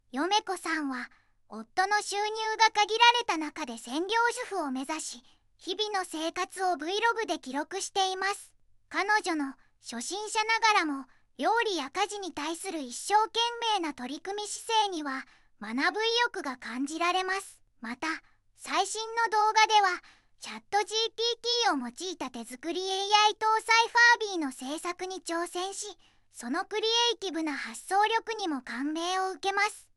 VOICEVOX（ずんだもん）
VOICEVOX : ずんだもん